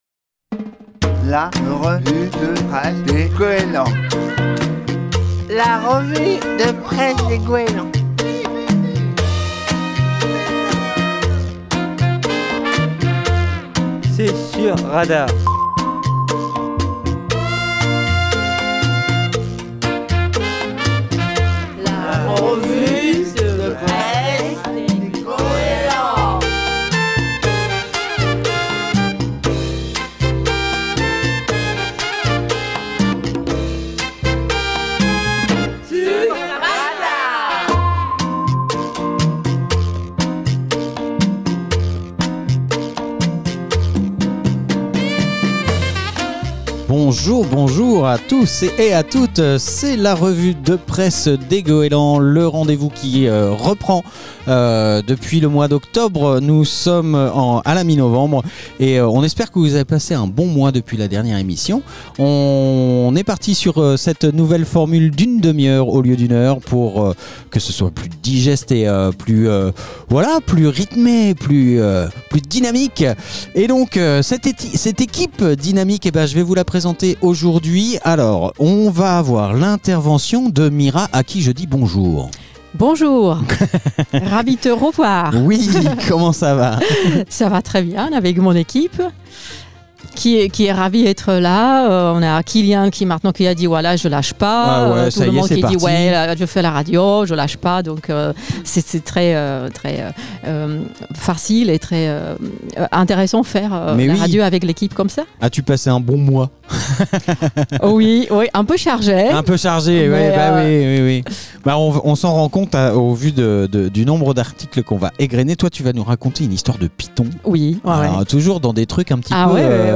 Les usagers du foyer d'activités des Goélands de Fécamp font leur revue de presse tous les 2ème mardis de chaque mois sur Radar